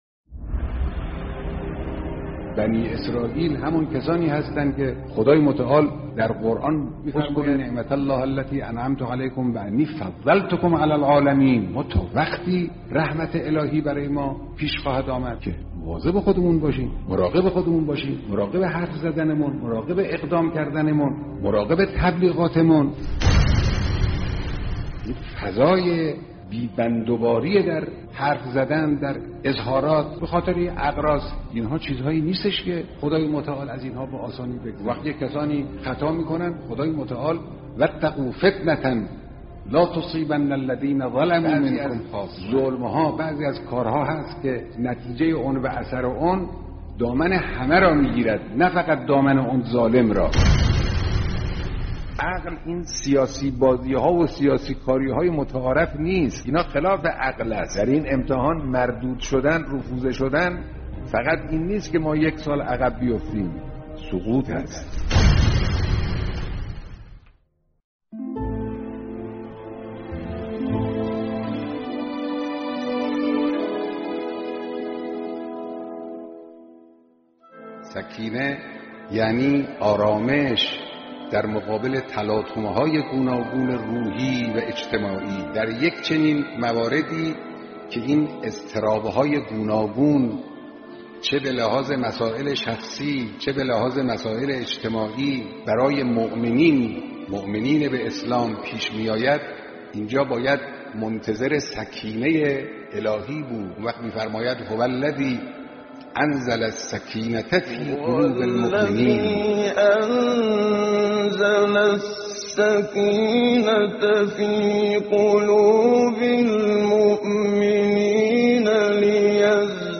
کلیپ صوتی از بیانات رهبر انقلاب در نماز جمعه‌ی بیست و نهم خردادماه هشتاد و هشت.